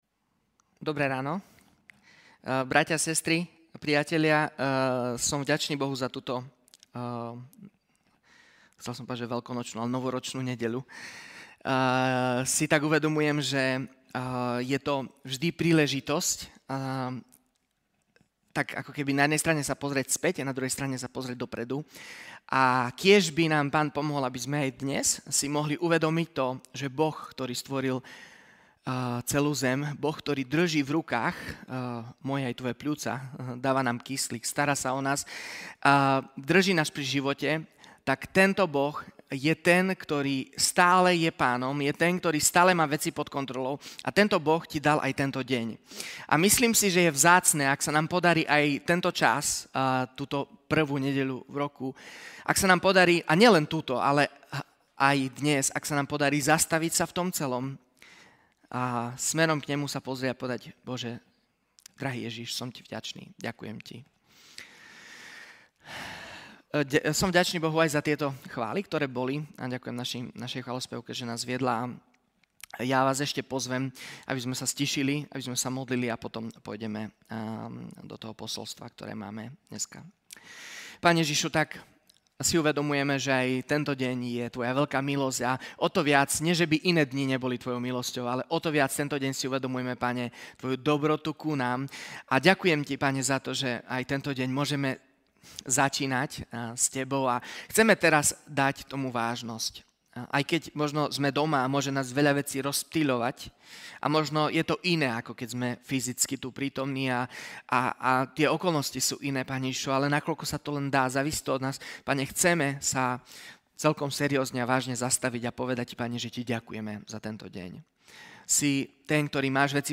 Vypočujte si kázne z našich Bohoslužieb
Novorocny-prihovor.mp3